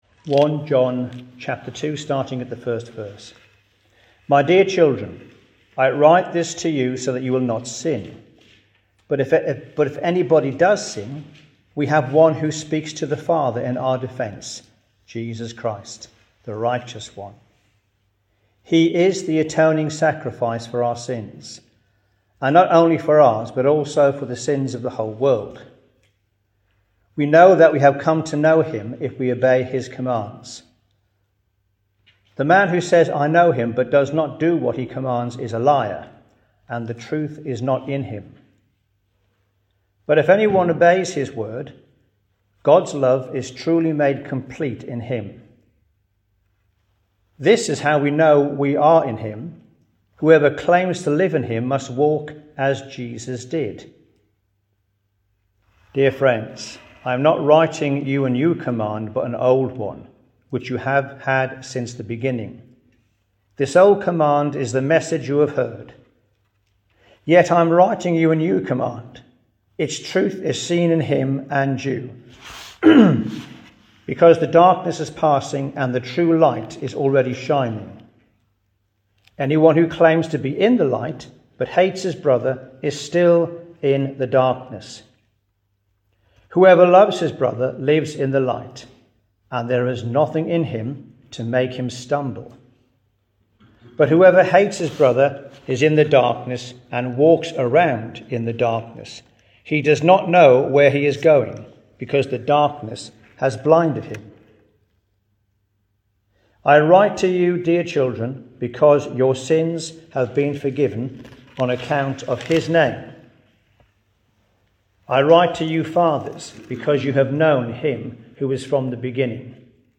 1 John 2:1-14 Service Type: Thursday 9.30am Topics